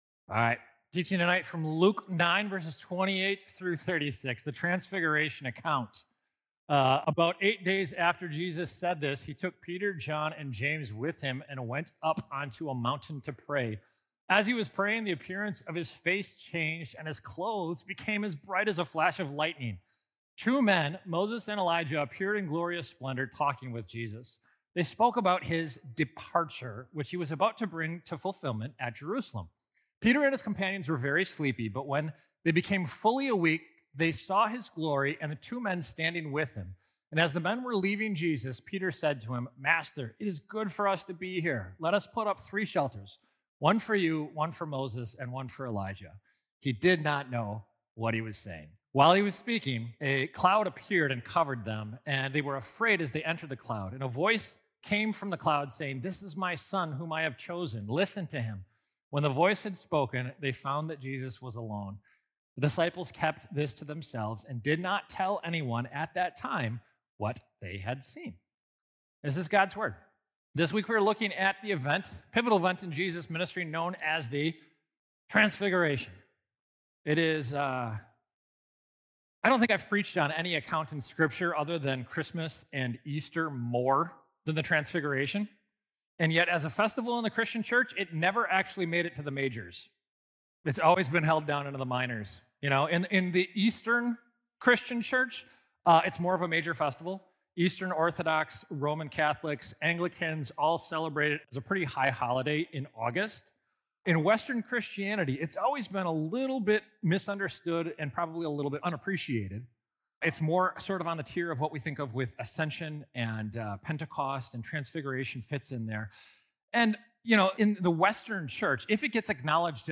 St Marcus MKE Sermons « » No Other Gospel | Galatians: Relearning the Gospel Daily